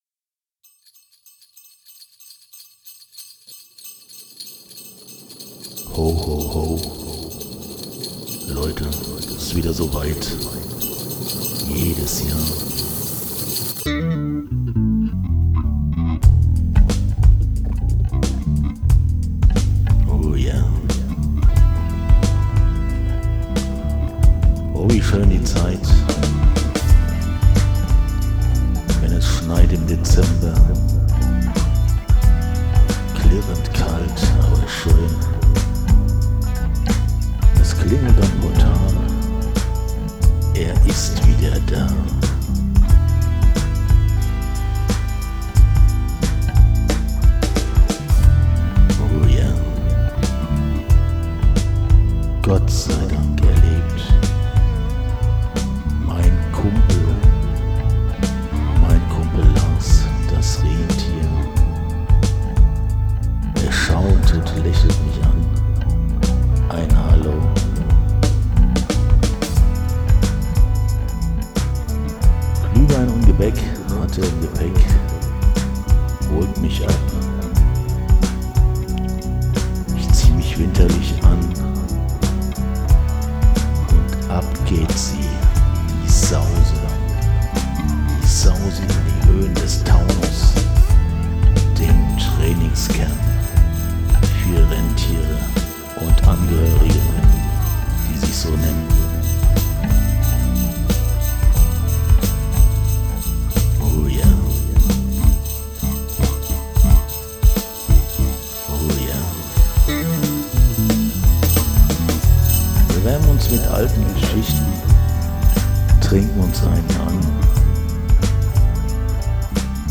Cooler Sound :D . Vielleicht auf den Vocals noch ein bisschen mehr Air.
Dieses Jahr dachte ich mir, machst Du mal ein Lied auf Plattdeutsch... schnell was zusammen gereimt und die KI was singen lassen. Gesang KI (nachbearbeitet) , Schlagzeug geklaut aber immerhin Text, Gitarren und Geräusche von mir...